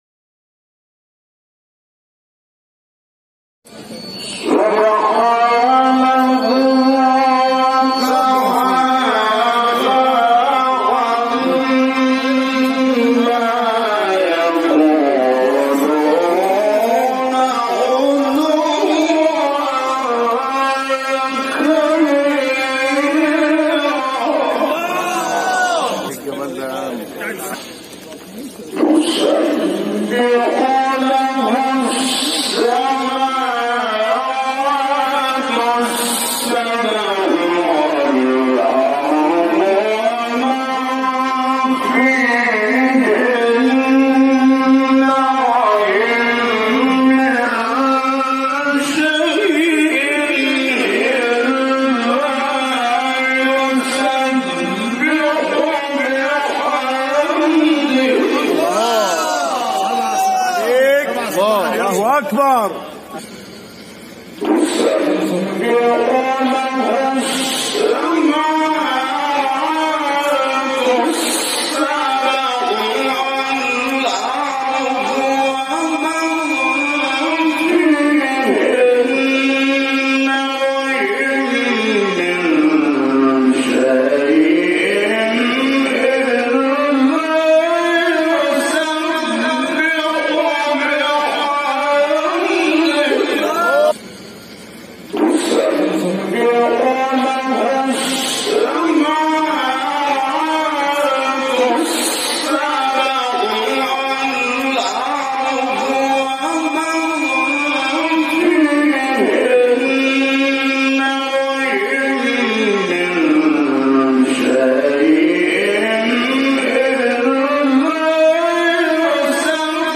به تقلید از شیخ محمد رفعت
دانلود تلاوت قرآن